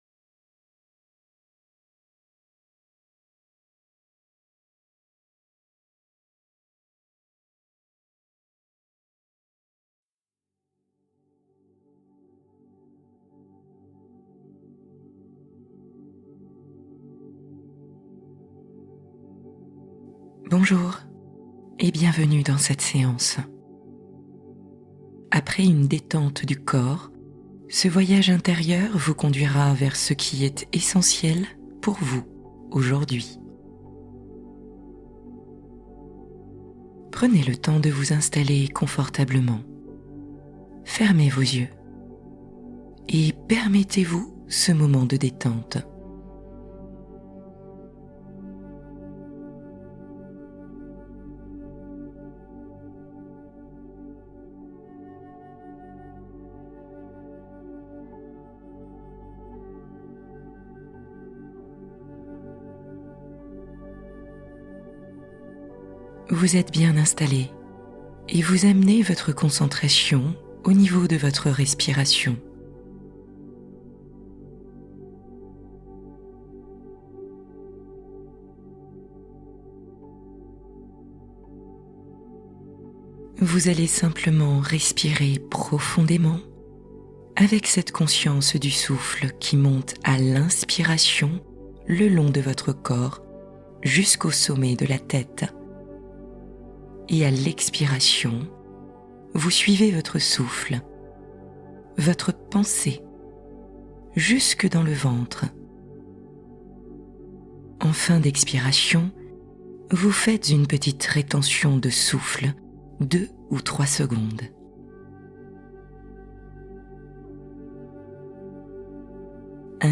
Retrouvez l'Essentiel : Méditation guidée pour vous reconnecter à ce qui compte vraiment